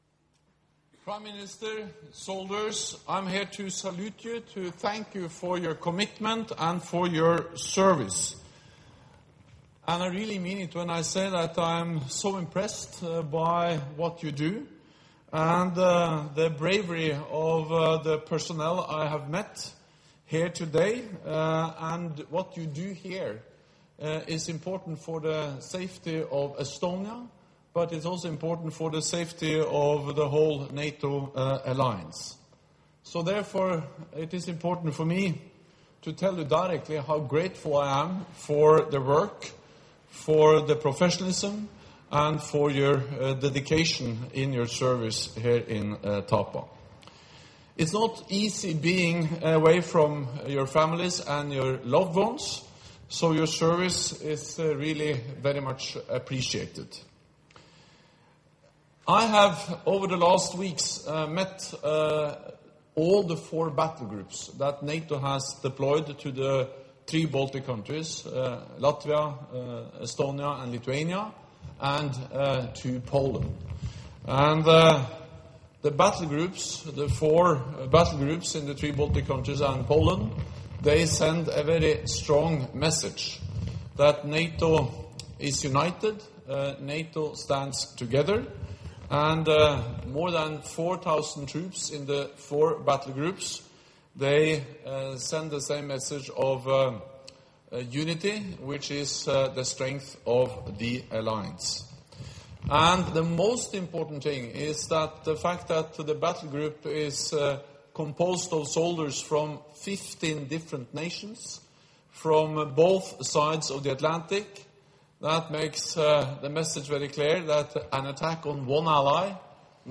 Remarks to eFP troops by NATO Secretary General Jens Stoltenberg 06 Sep. 2017 | download mp3 Joint press point by NATO Secretary General Jens Stoltenberg and the Prime Minister of the Republic of Estonia, Jüri Ratas 06 Sep. 2017 | download mp3